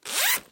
Звуки ширинки
Звуки расстегиваемой ширинки быстро